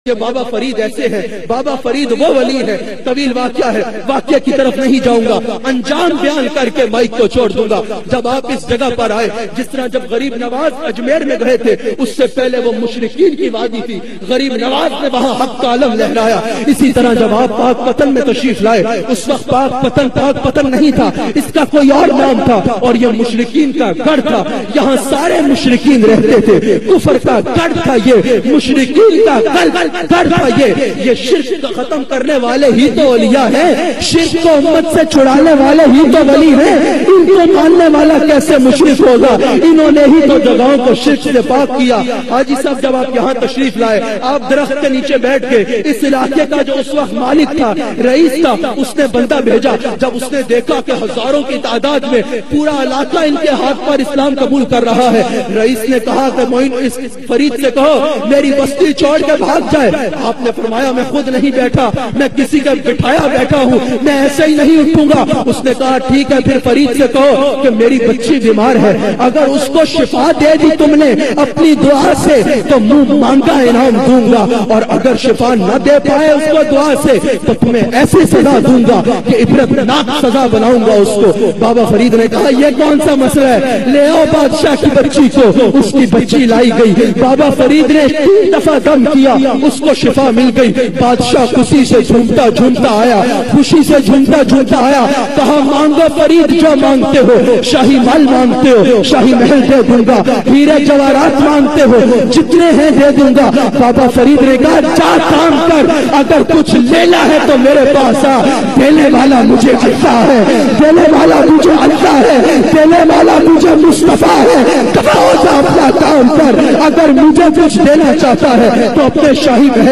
Hazrat Baba Fareed Pakpattan Shareef bayan mp3